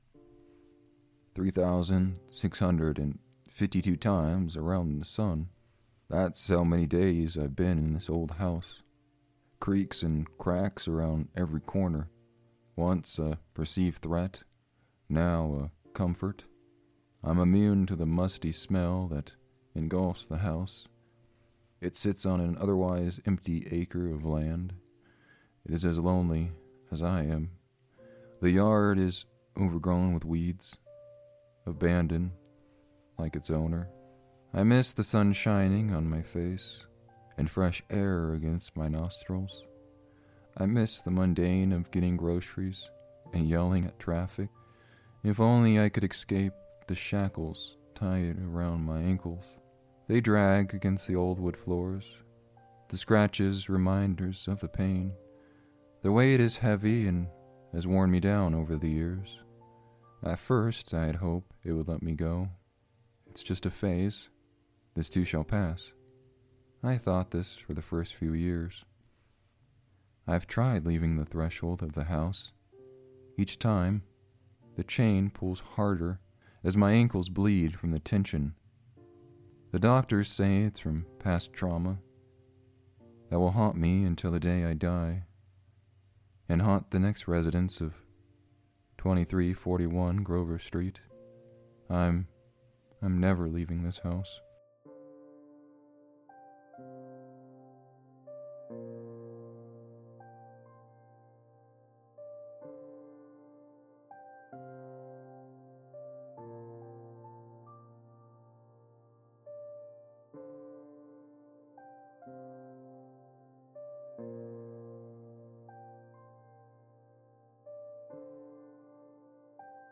Format: Audio Book
Voices: Solo
Narrator: First Person
Genres: Thriller and Psychological
Soundscape: Voices only